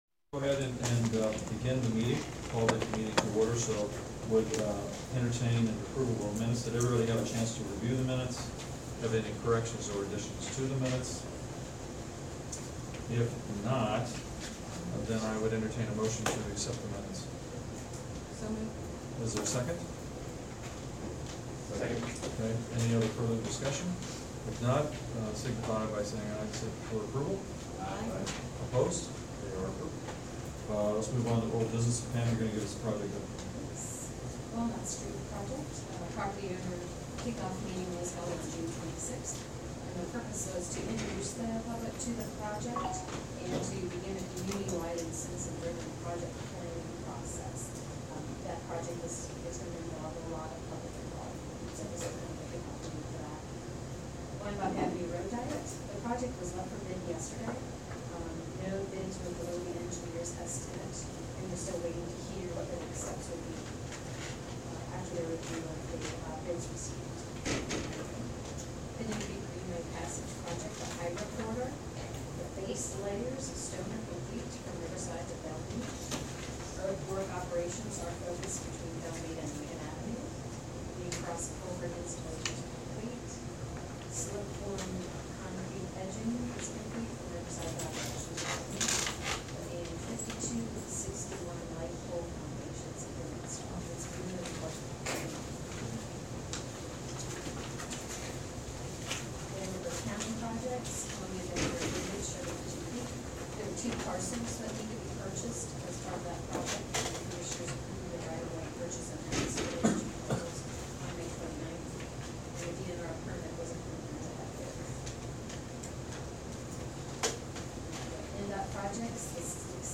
The Evansville MPO Technical Committee meetings are held in the Evansville Civic Center, Room 318 at 10:00 a.m.